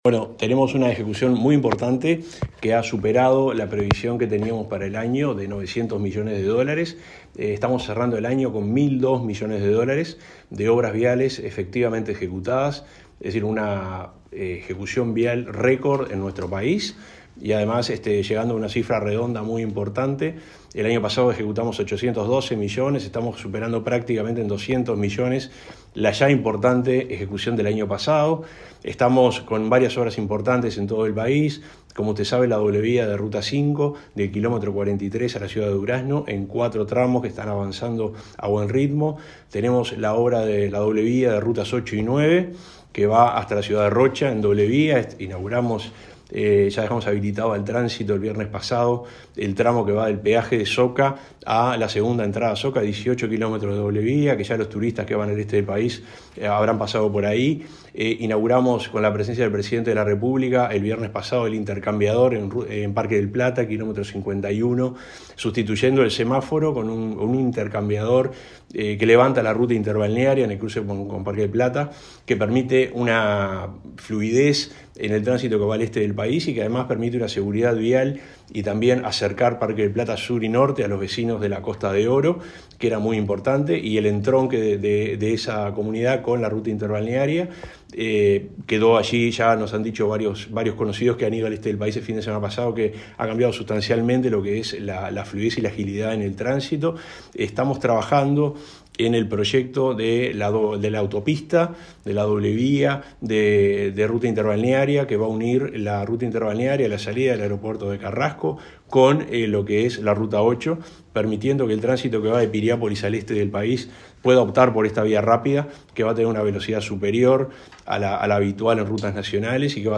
Entrevista al subsecretario de Transporte y Obras Públicas, Juan José Olaizola